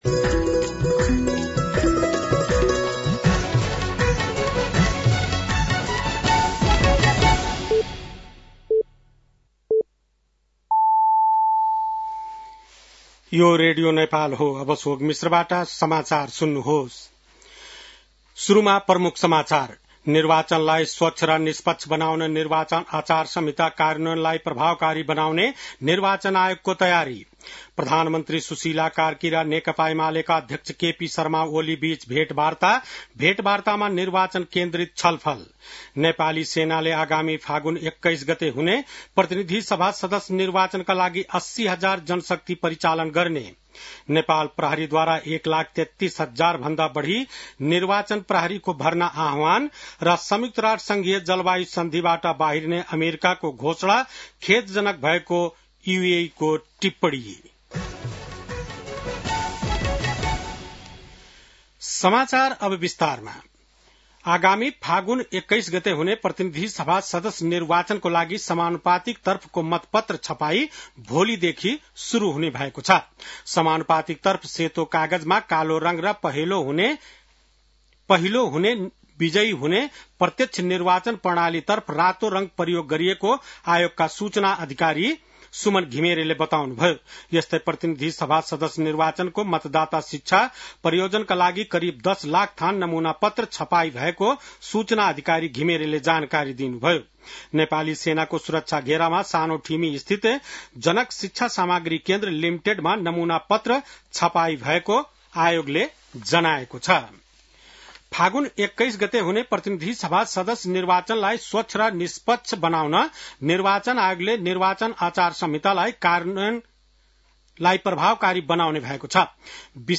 बेलुकी ९ बजेको नेपाली समाचार : २४ पुष , २०८२
9-PM-Nepali-NEWS-9-24.mp3